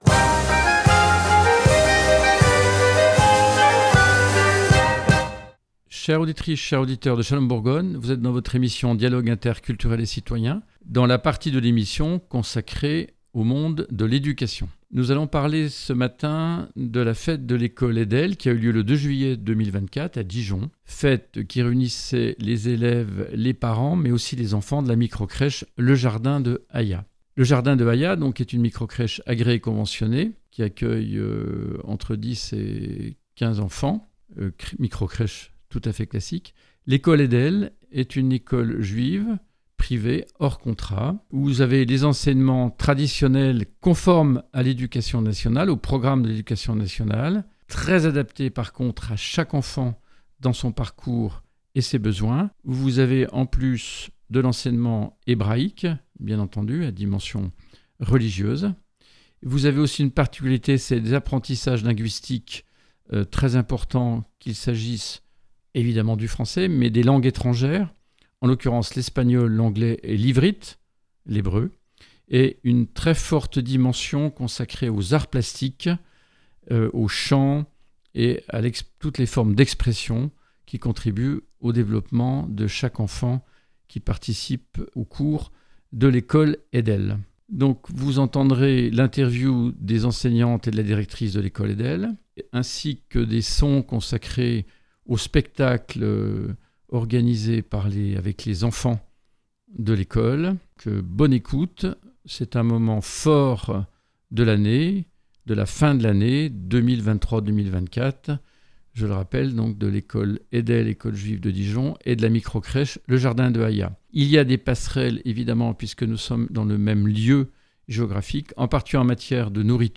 Le 2 juillet 2024 s’est tenue la fête de fin d’année de la micro-crèche « Le Jardin de Haya » et de l’école privée EDEL à Dijon.
Reportage et interview